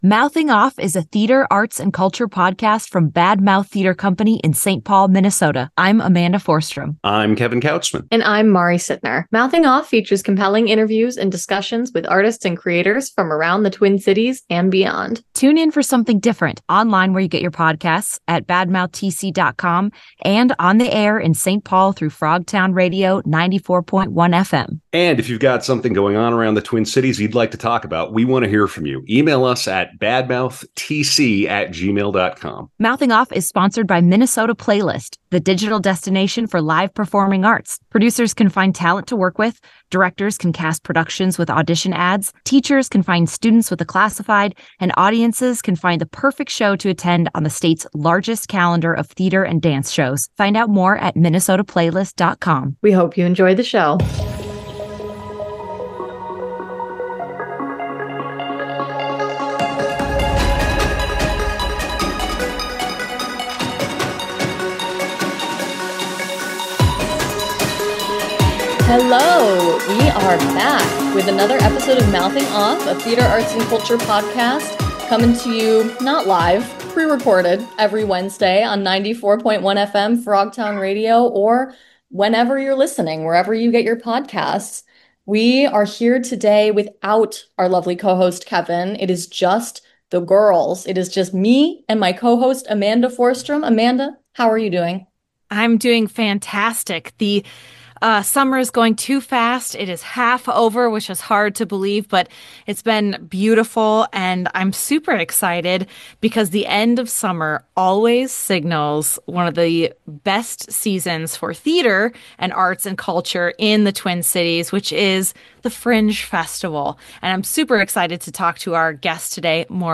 This episode features original music from the show.